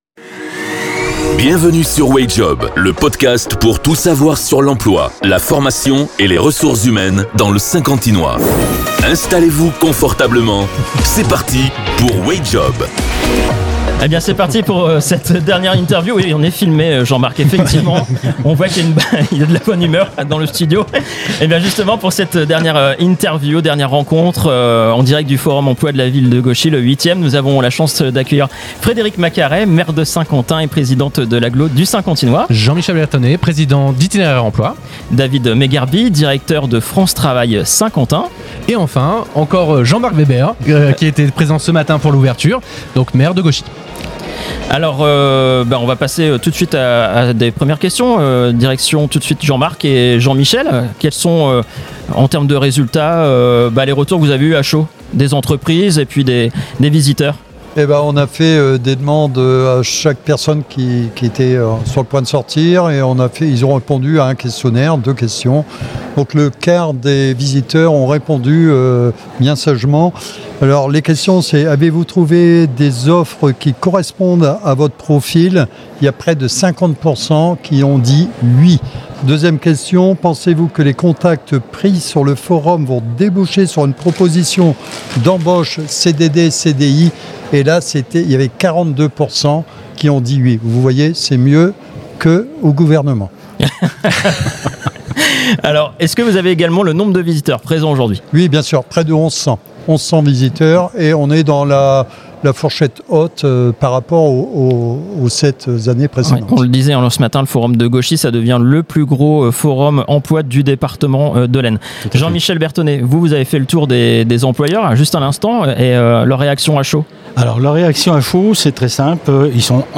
Dans cet épisode de Wayjob , nous revenons en direct sur la 8ᵉ édition du Forum de l'Emploi de Gauchy